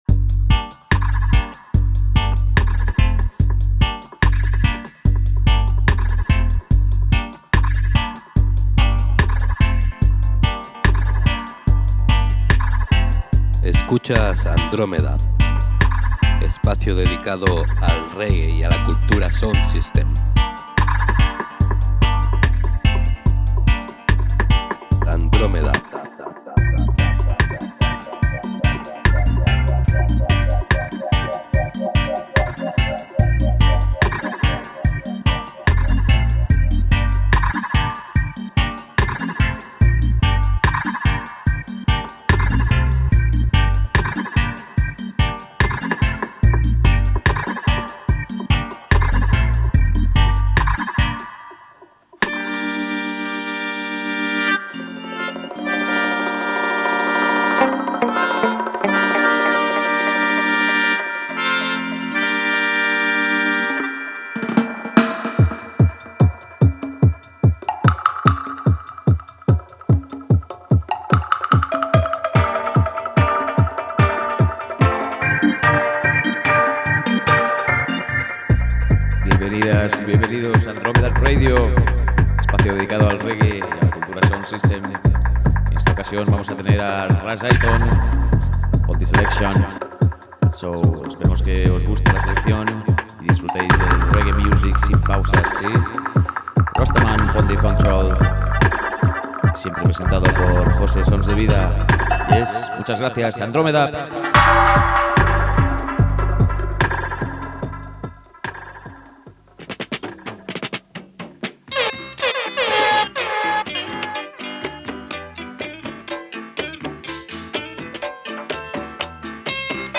on 107.5 FM